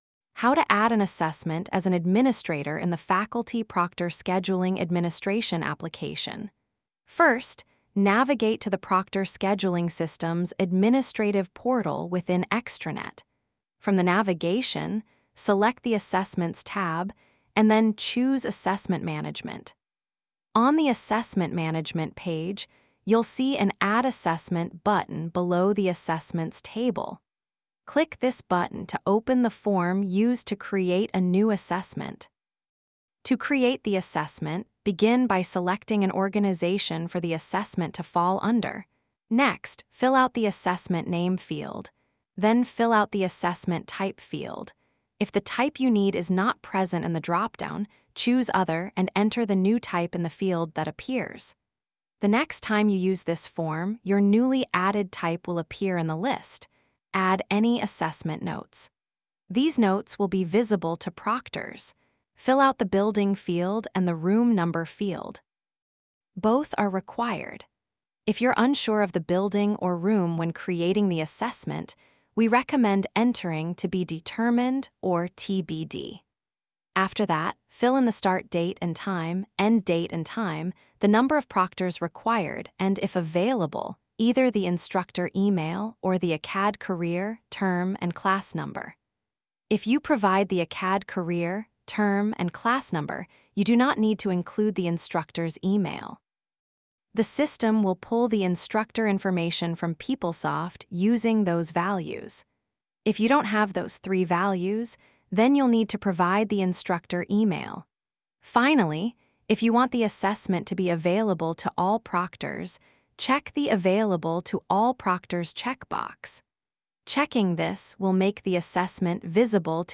AI-TTS/script2.wav